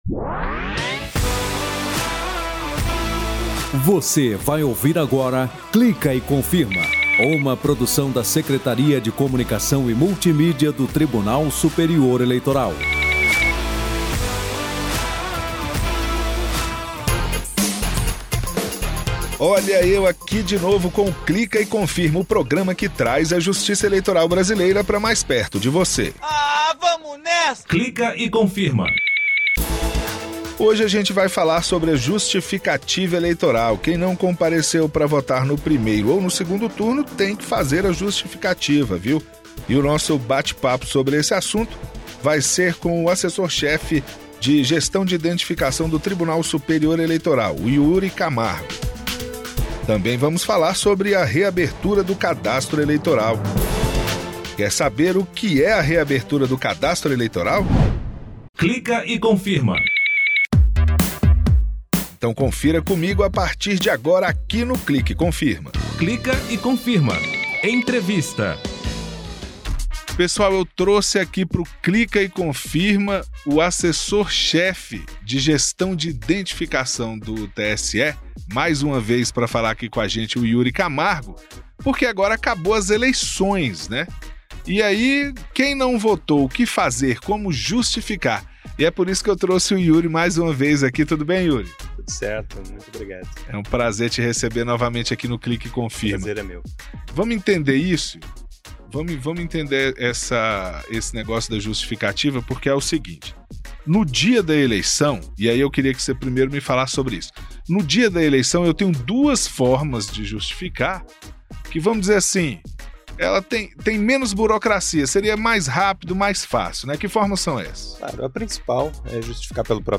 traz um bate-papo sobre justificativa eleitoral